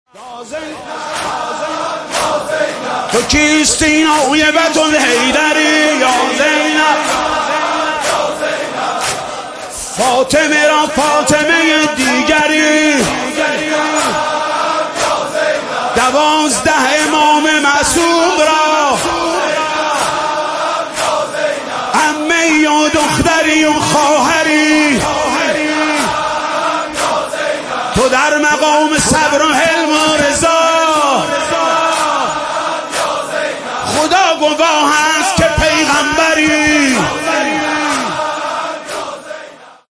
شب چهارم محرم
نوحه جديد